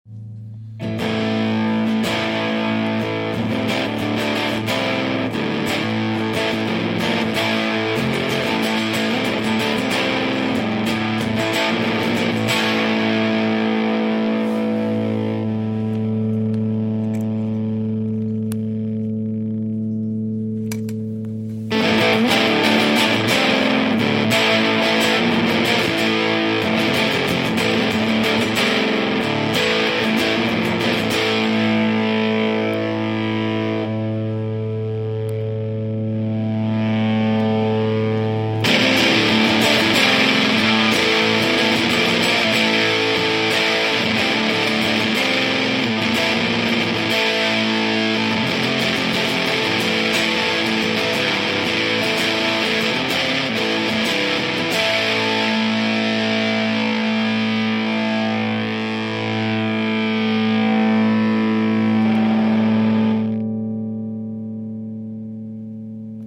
Plobo auf Anschlag und Cariol etwas runter.
SC meiner RG550 durchs Pedal in nen Combo, niedrige Zimmerlautstärke, Aufnahme mit dem Fon.